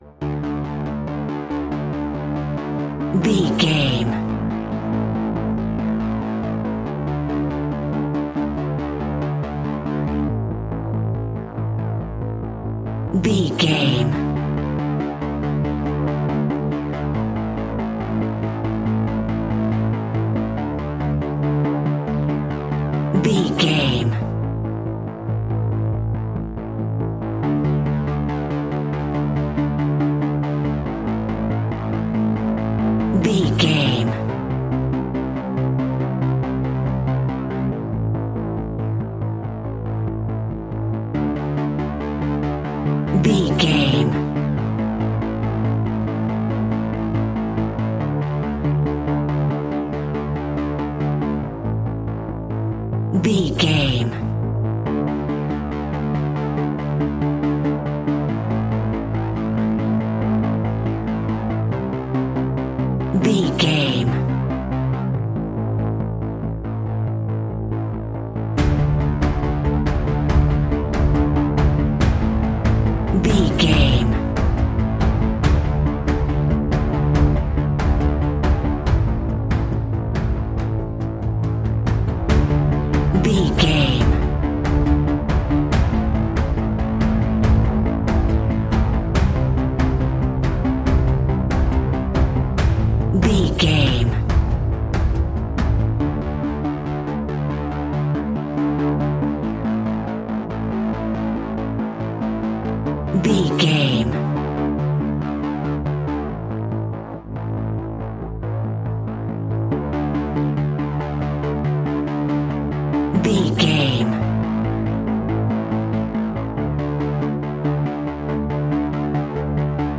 In-crescendo
Thriller
Aeolian/Minor
tension
ominous
eerie
horror music
Horror Pads
horror piano
Horror Synths